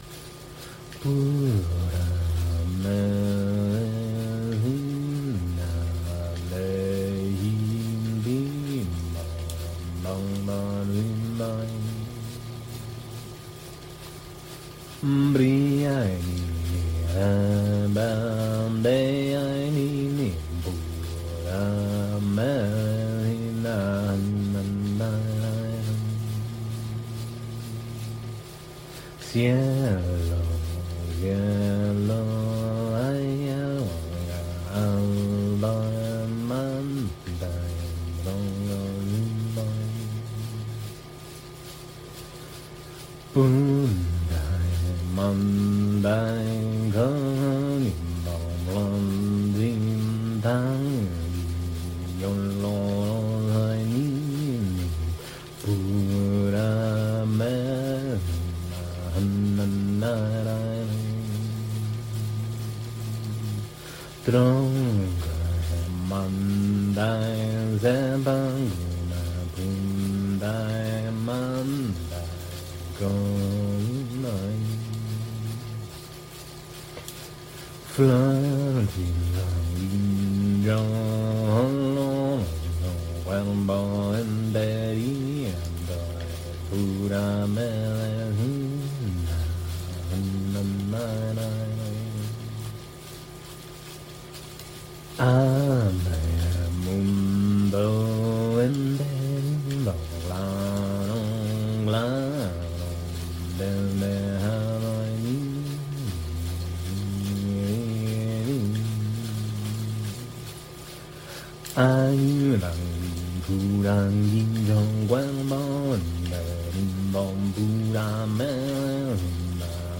Icaros — Transcendent Counsel - Trauma healing, psychedelic integration, ayahuasca integration
Plant Medicine Songs
Live+Icaro.mp3